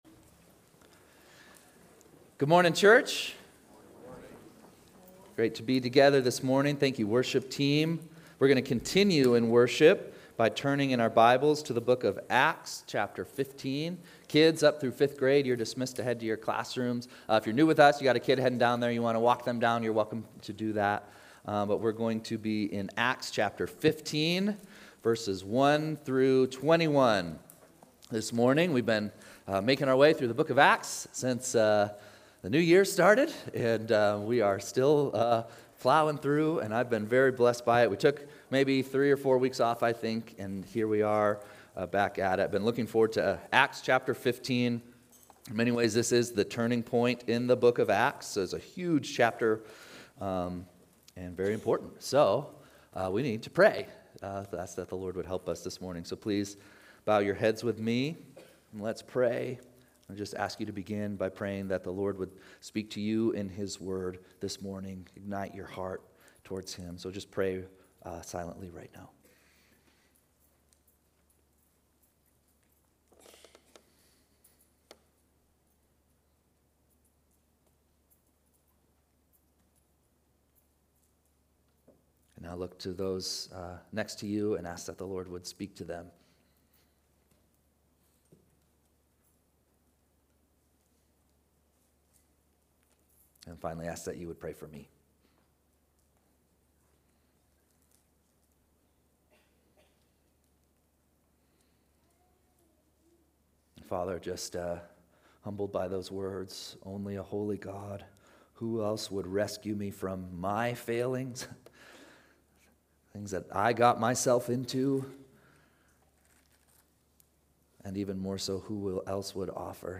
92825-Sunday-Service.mp3